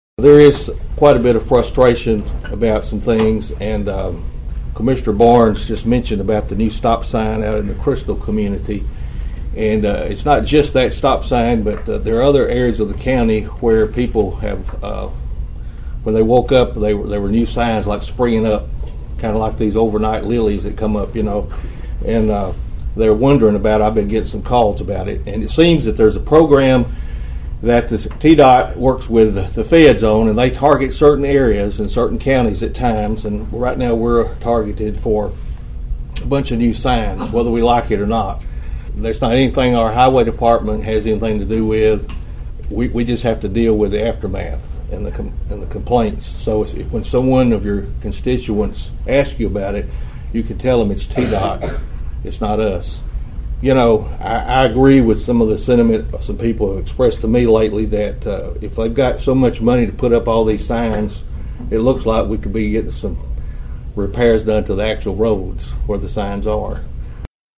Concern was raised at Monday’s Obion County Commission meeting pertaining to a Department of Transportation response to a dangerous intersection.
County Mayor Steve Carr said he has had calls about new signage in the county.(AUDIO)